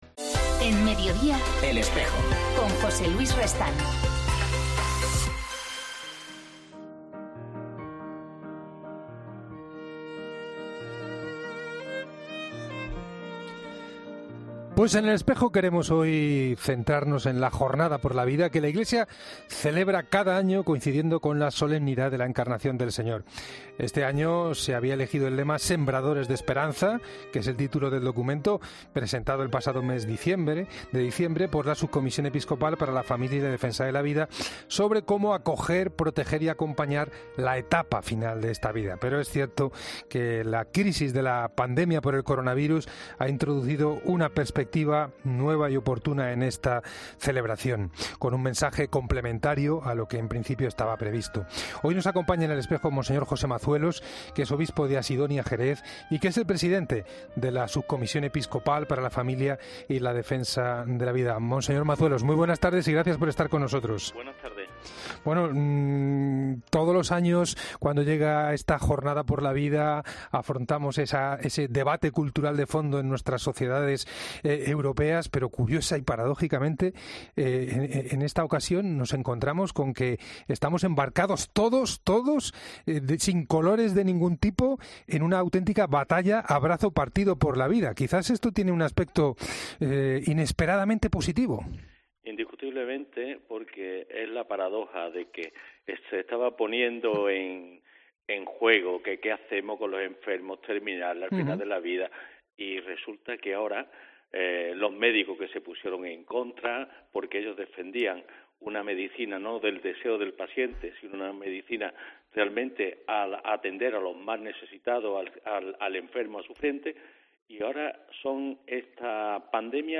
Mons. José Mazuelos Pérez, obispo de la diócesis Asidonia-Jerez y también presidente de la Subcomisión de Familia y Defensa de la Vida ha querido lanzar un mensaje en este día, desde los micrófonos de El Espejo.